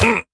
Minecraft Version Minecraft Version latest Latest Release | Latest Snapshot latest / assets / minecraft / sounds / mob / vindication_illager / hurt2.ogg Compare With Compare With Latest Release | Latest Snapshot
hurt2.ogg